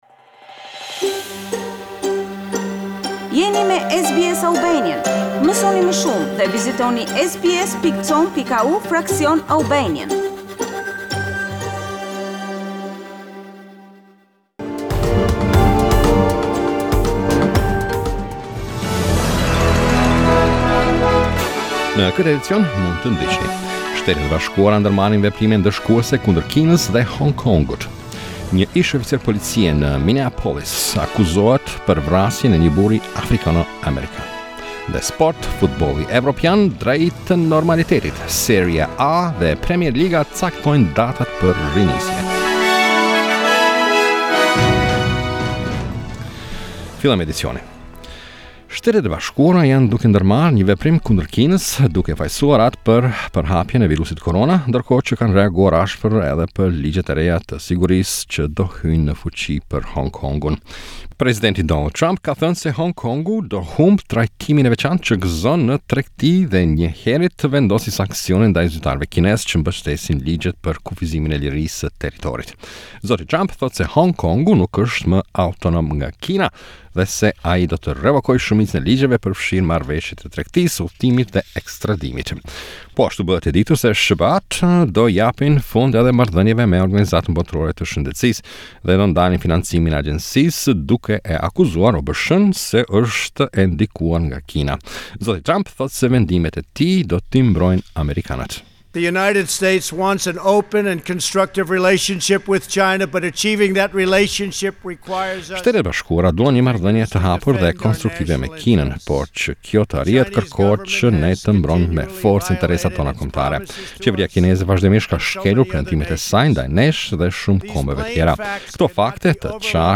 SBS News Bulletin - 30 May 2020